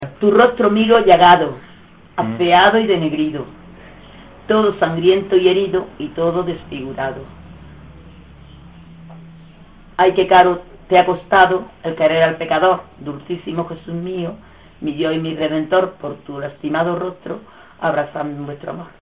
Materia / geográfico / evento: Canciones religiosas Icono con lupa
Zafarraya (Granada) Icono con lupa
Secciones - Biblioteca de Voces - Cultura oral